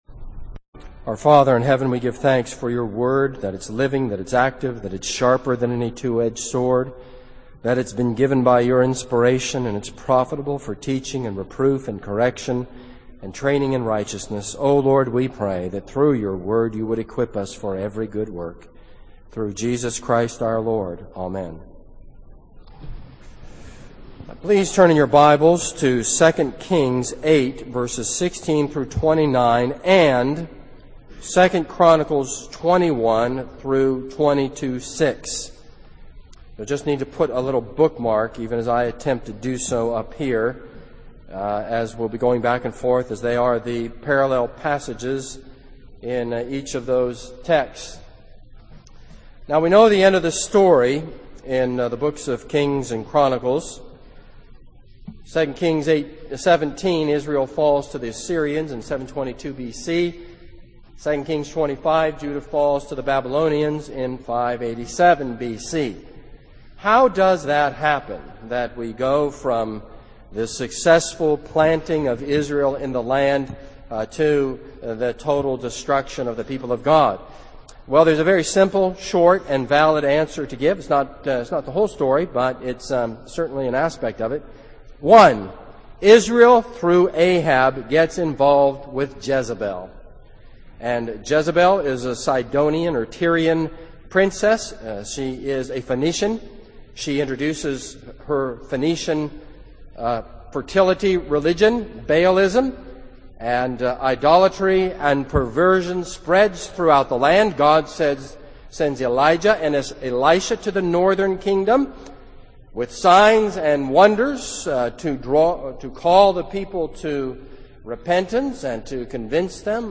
This sermon is based on 2 Kings 8:16-29 and 2 Chronicles 21:1-22:6.